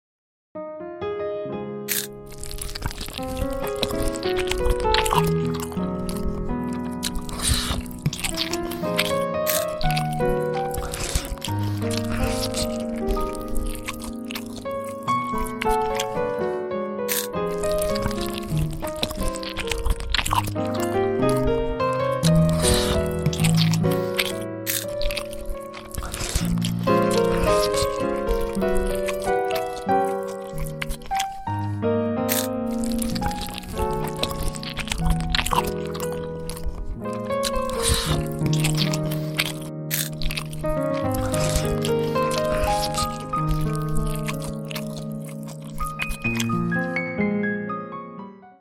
Asmr Mukbang Animation